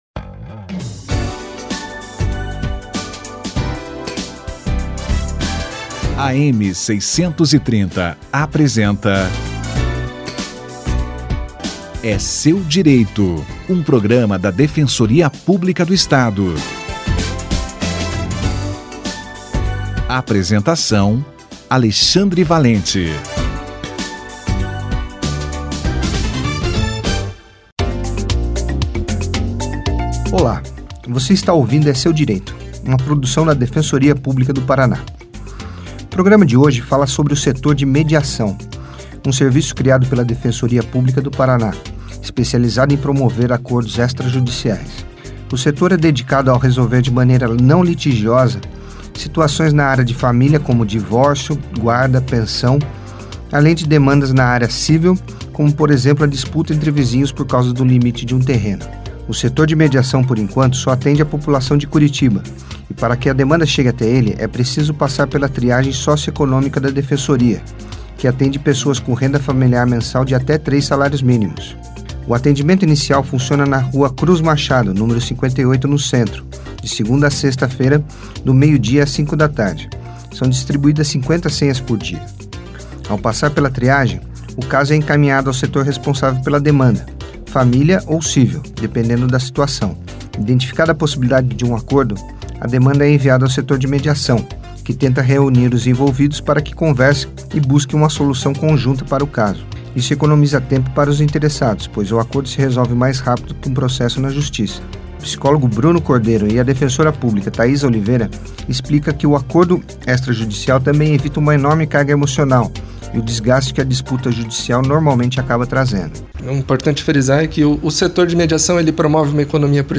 Setor de mediação na Defensoria Pública - Entrevista